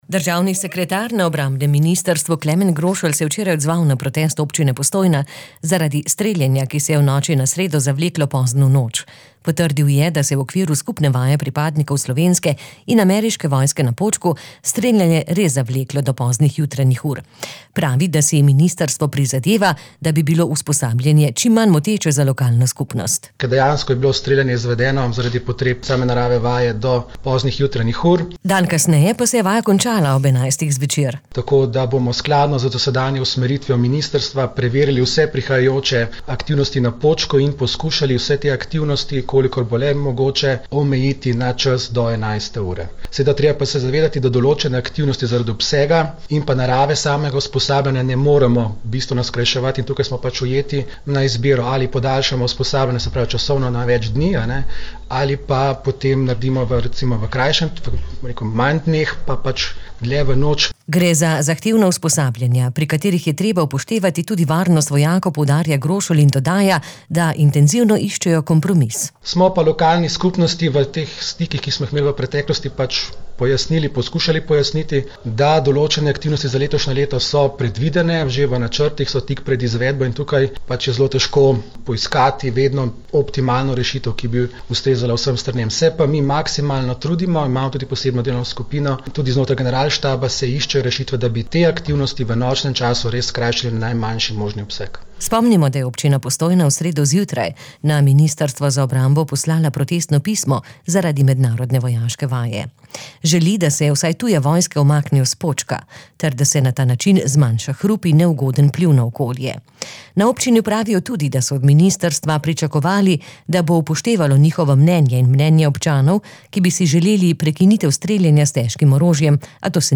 Grošelj je po današnji seji vlade v izjavi za medije pojasnil, da se je prejšnjo noč streljanje na Počku zavleklo zaradi potreb mednarodne vojaške vaje.